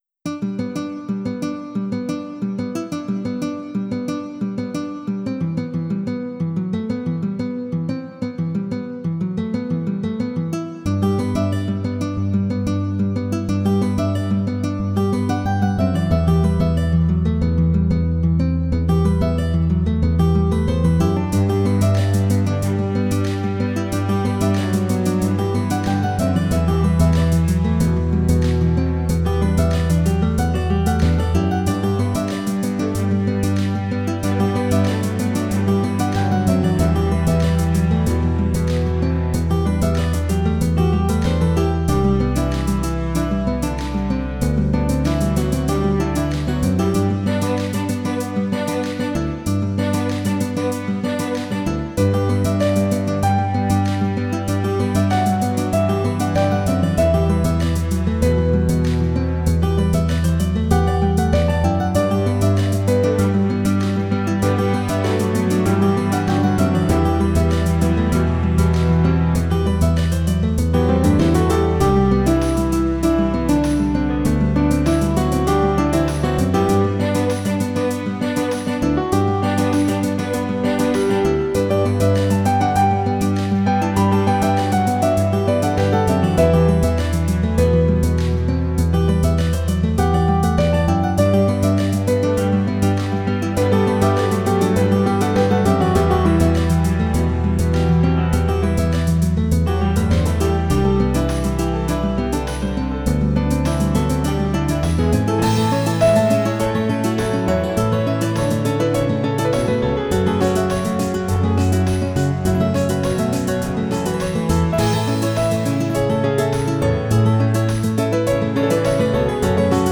Tags: Piano, Strings, Guitar, Percussion